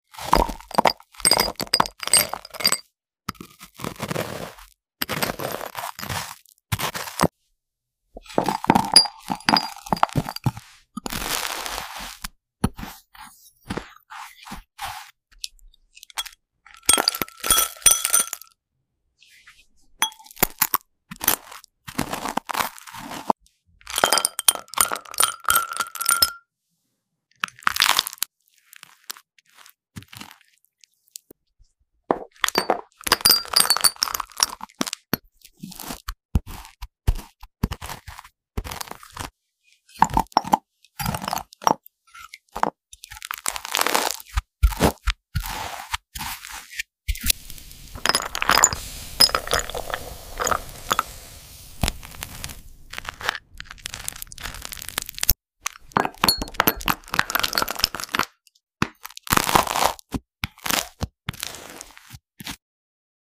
Which Macaron Food ASMR Spread Sound Effects Free Download